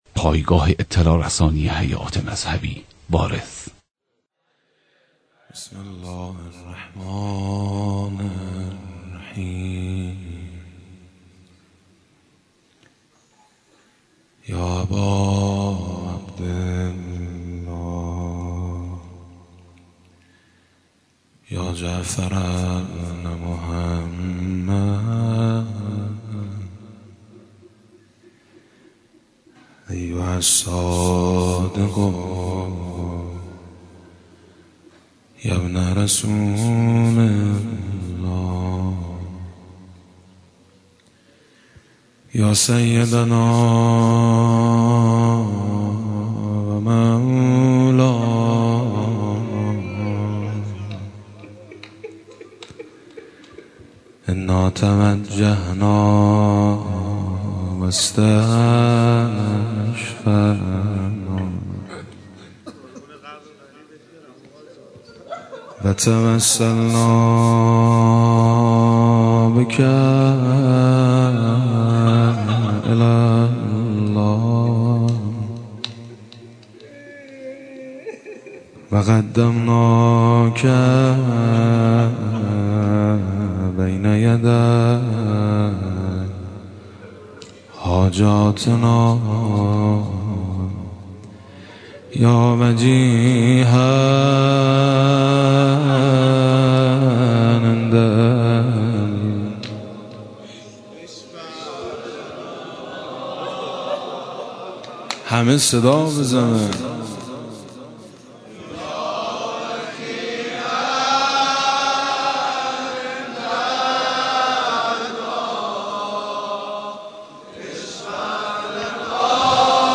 مداحی حاج میثم مطیعی به مناسبت شهادت امام صادق (ع)